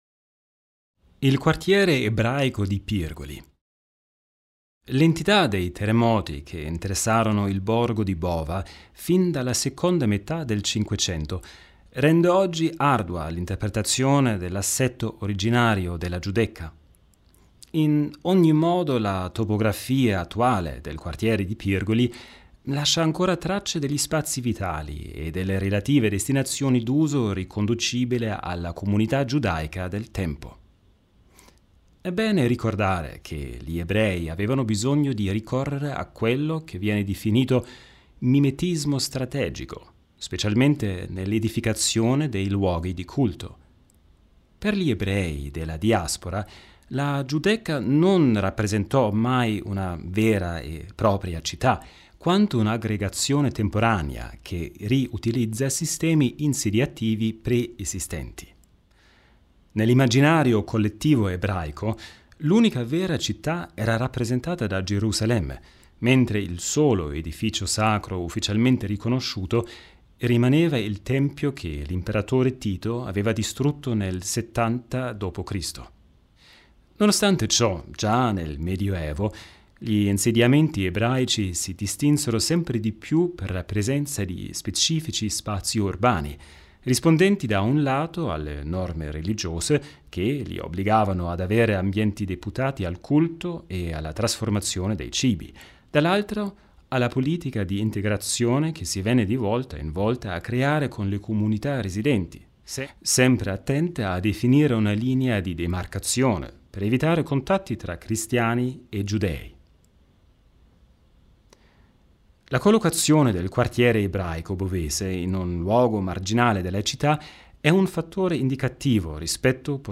HOME AUDIOGUIDA DELLA GIUDECCA